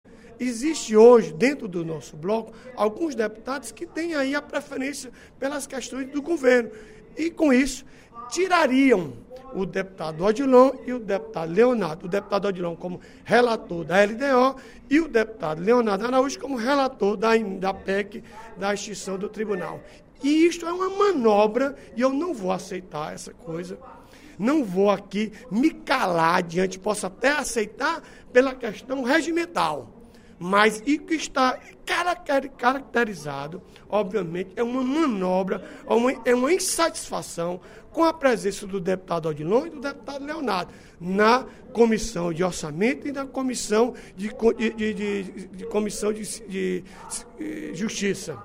O deputado Odilon Aguiar (PMB) criticou, durante o primeiro expediente da sessão plenária desta quarta-feira (07/06), a base do Governo, que estaria “interferindo” nas atividades parlamentares de oposição.